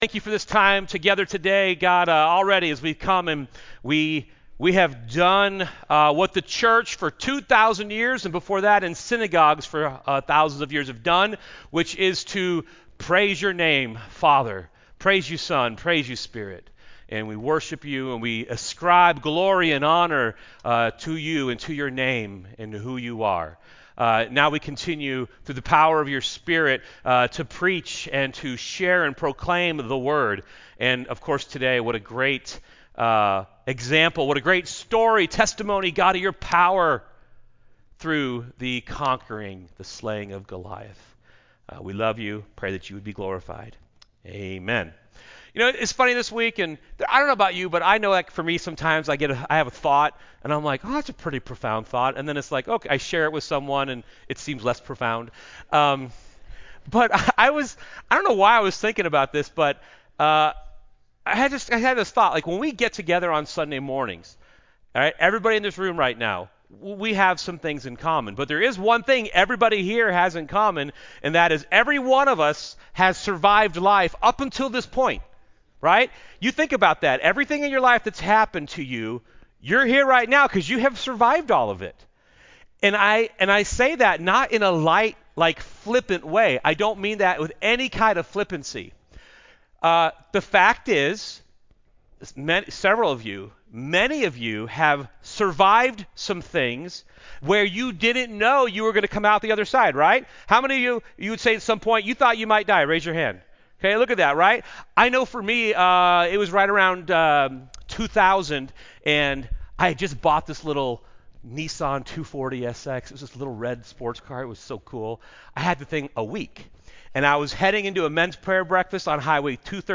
A message from the series "The Life of David."